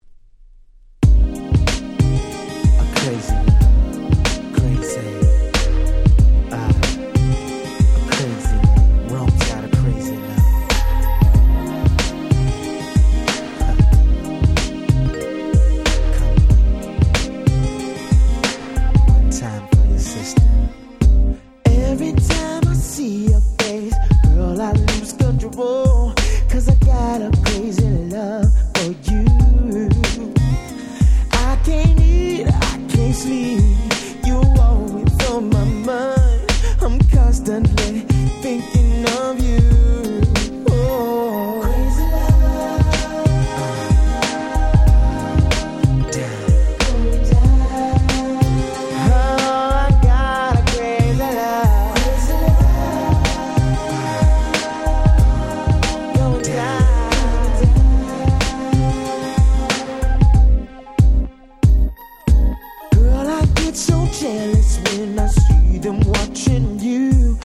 97' Very Nice R&B !!